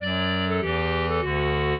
clarinet
minuet11-11.wav